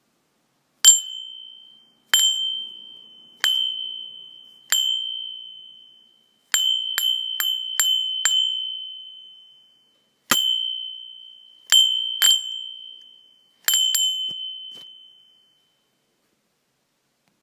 Liatinový zvonček s krásnym zvukom je dokonalým doplnkom v chalupárskom štýle.
Zvoneček
Materiál: litina
zvonecek.m4a